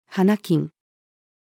花金-female.mp3